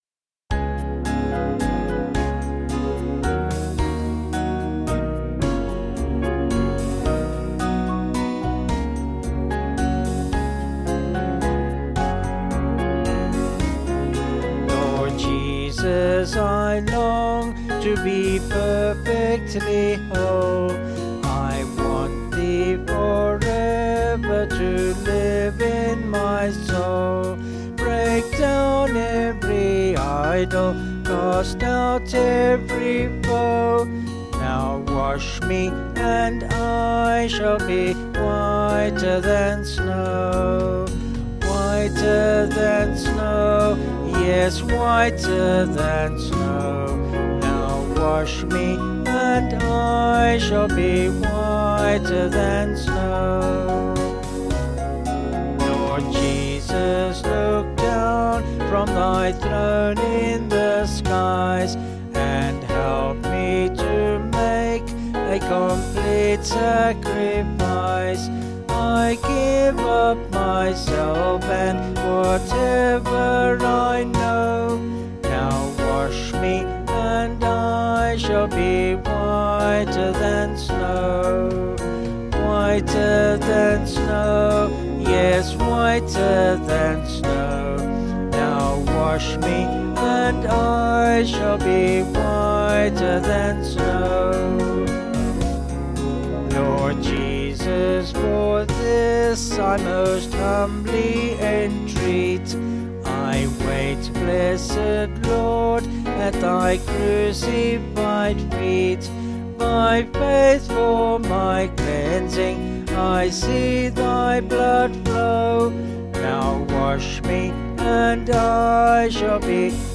Vocals and Band   265.2kb Sung Lyrics